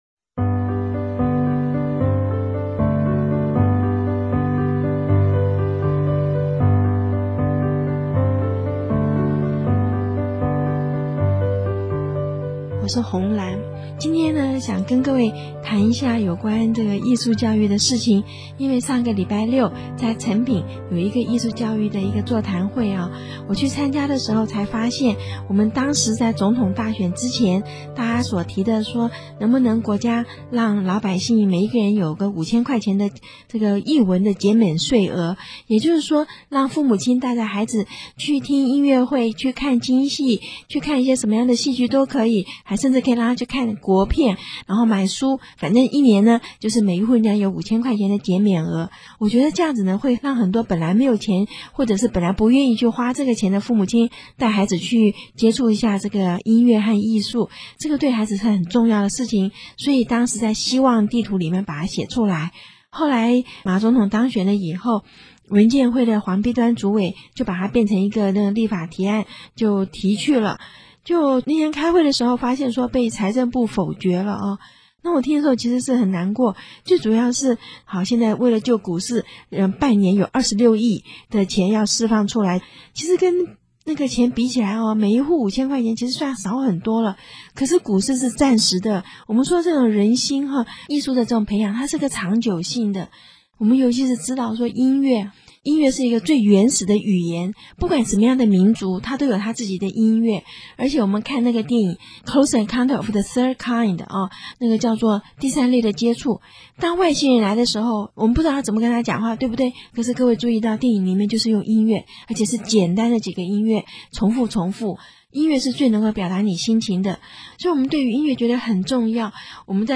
有聲書第三輯